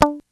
_GUITAR PICK 2.wav